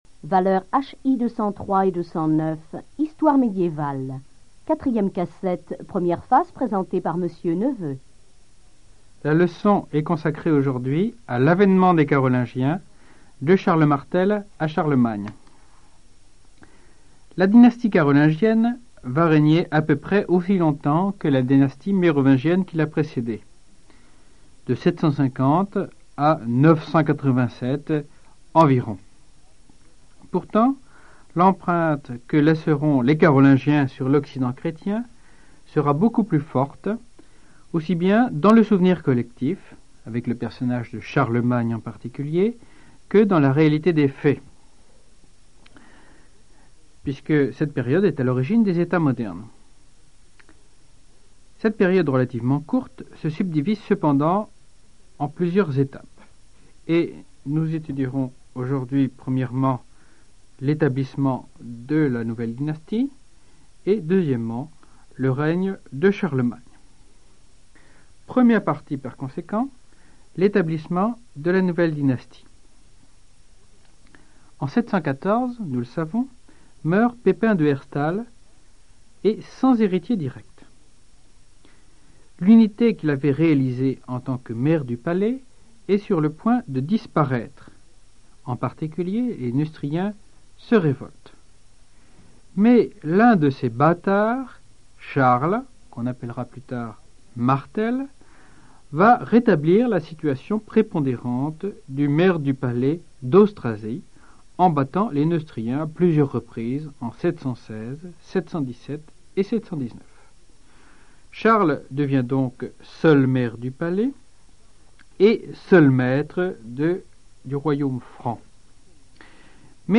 Archives cours audio 1987-1988